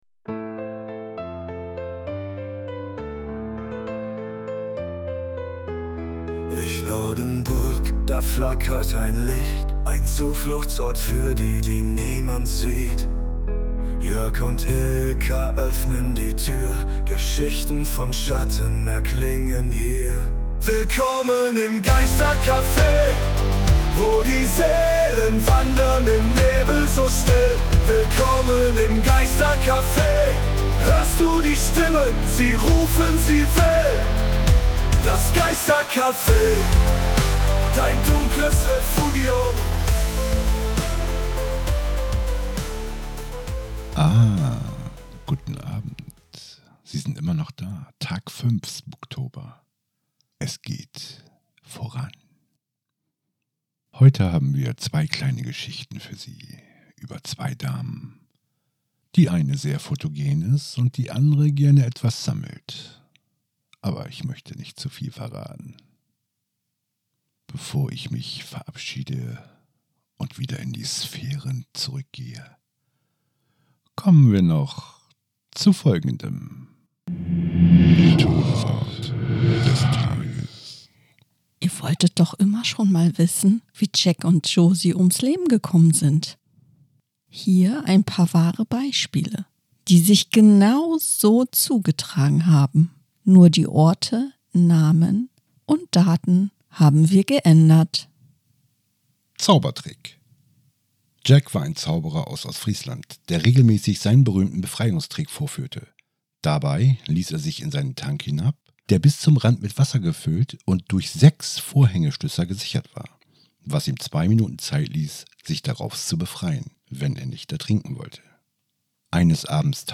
In der fünften Episode des Spuktober 2025 werfen wir abermals einen Blick auf das, was euch in den kommenden Nächten erwartet, und starten mit einer Geschichte, die euch garantiert das Blut in den Adern gefrieren lässt. Dazu gesellen sich einige unserer Freunde, die den Spuktober mit ihren Stimmen noch lebendiger und gruseliger machen.